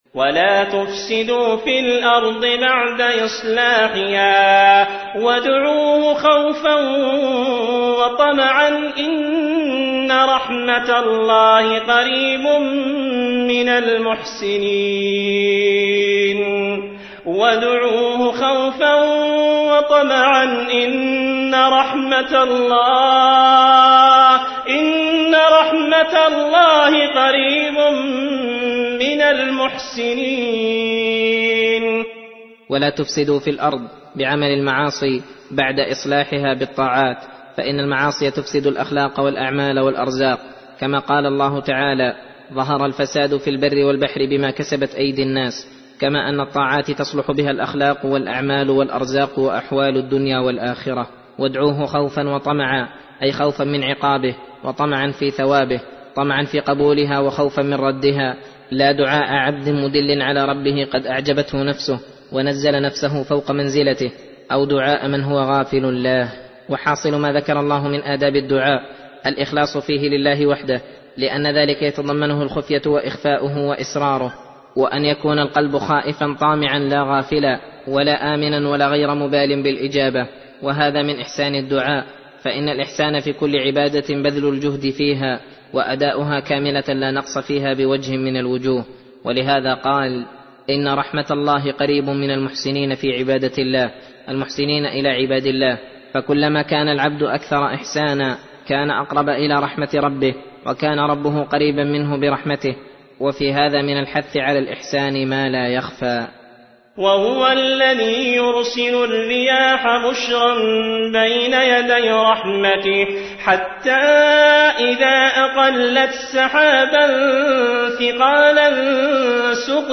درس (4) : تفسير سورة الأعراف : (56-72)